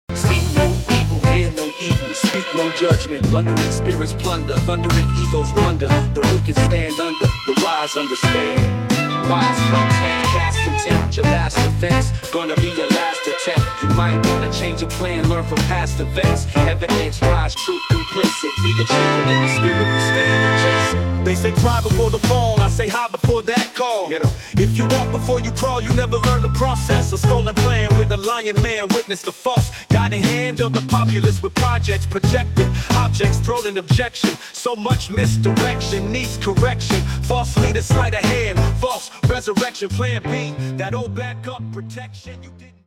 An incredible Hip Hop song, creative and inspiring.